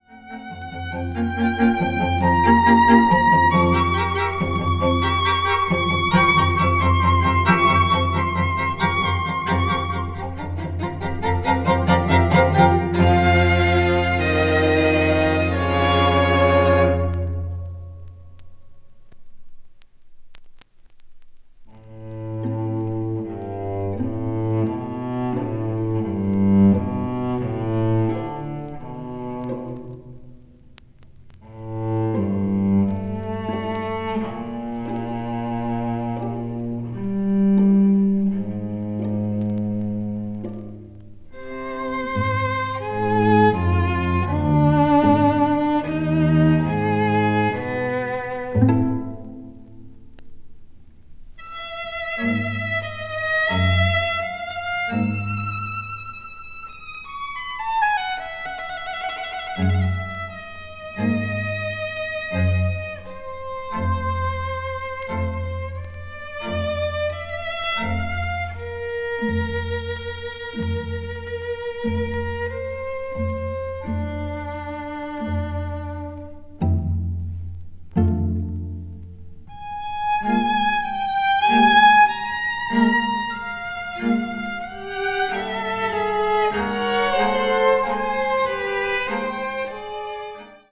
media : EX+/EX-(わずかにチリノイズが入る箇所あり,B前半:一部軽いチリノイズが入る箇所あり)
2nd String Quartet
20th century   avant-garde   chamber music   contemporary